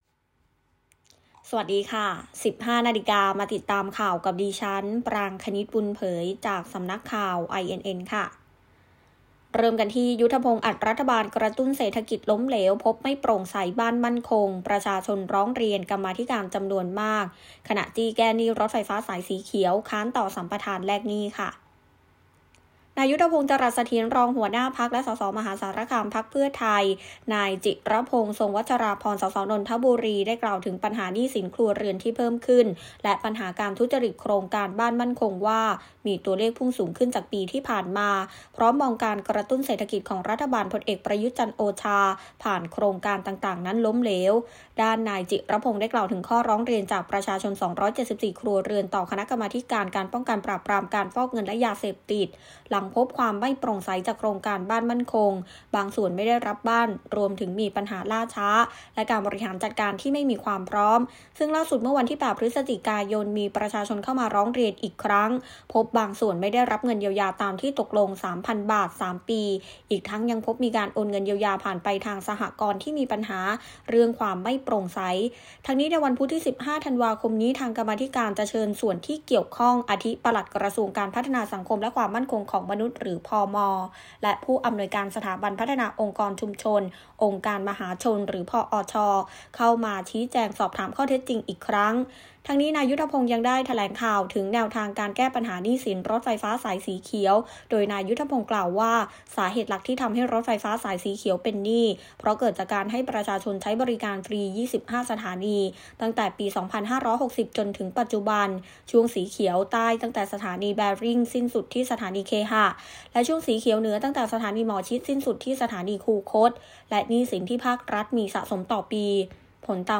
ข่าว